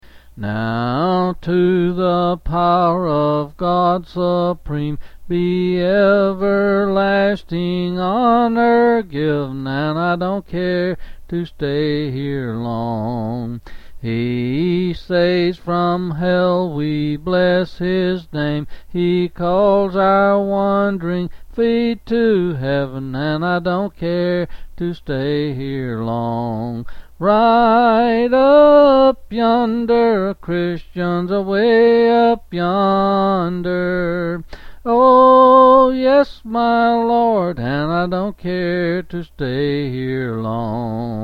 Quill Selected Hymn
L. M.